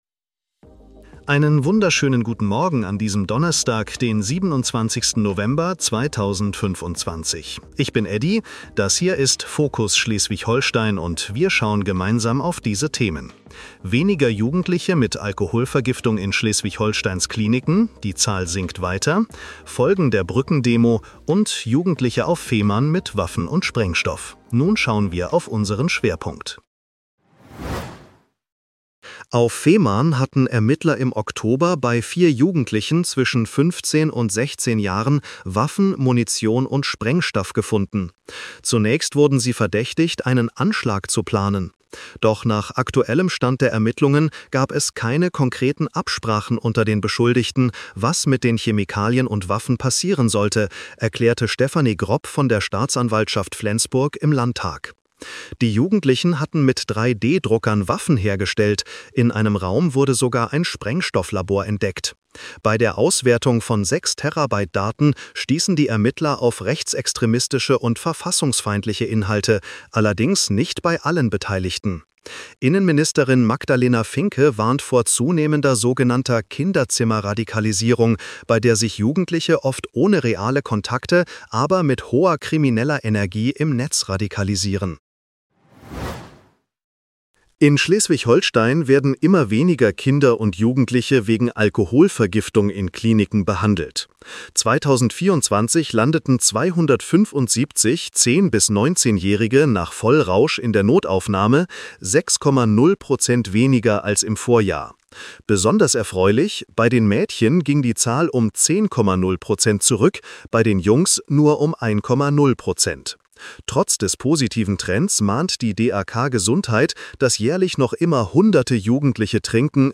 Nachrichten-Podcast bekommst Du ab 7:30 Uhr die wichtigsten Infos